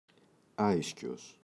άϊσκιος [‘aiscos]